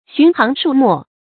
尋行數墨 注音： ㄒㄩㄣˊ ㄏㄤˊ ㄕㄨˇ ㄇㄛˋ 讀音讀法： 意思解釋： 尋行：一行行地讀；數墨：一字字地讀。